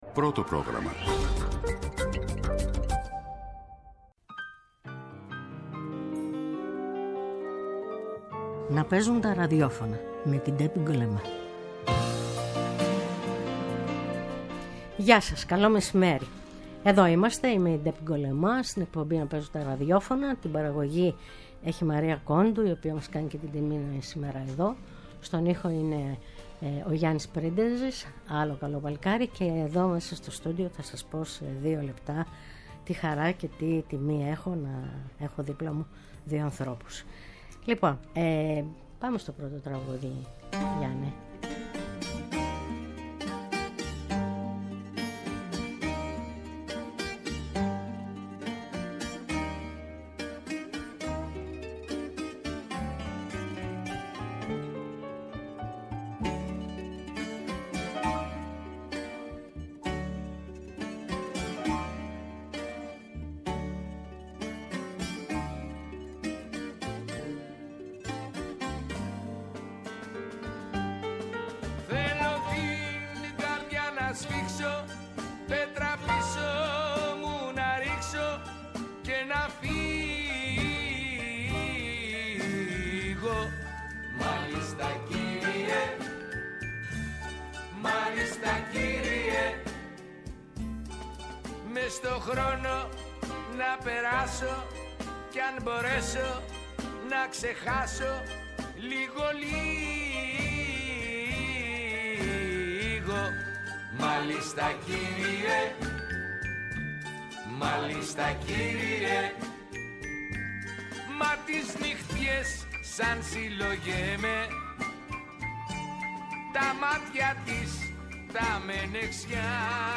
Η εκπομπή “Να παίζουν τα ραδιόφωνα” κάθε Σάββατο στη 1 το μεσημέρι φιλοξενεί στο studio ανθρώπους της Τέχνης -και όχι μόνο- σε ενδιαφέρουσες συζητήσεις με εξολογητική και χαλαρή διάθεση.